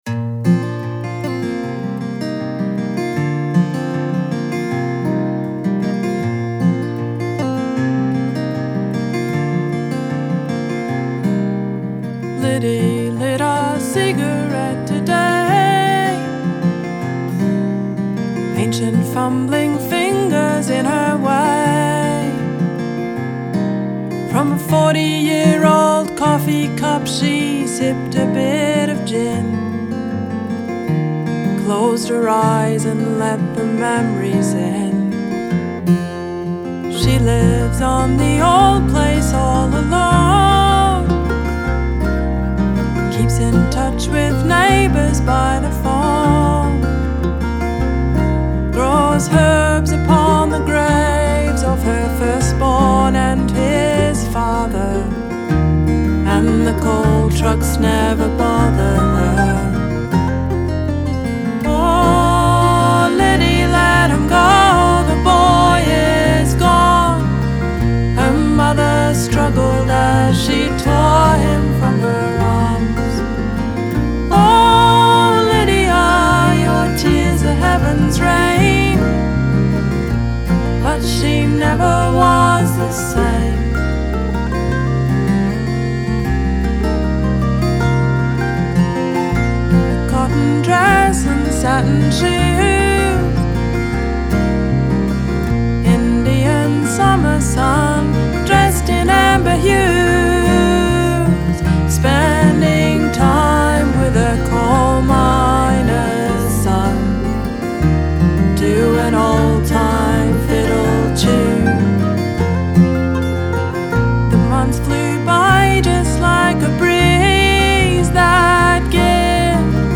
Acoustic Guitar Recordings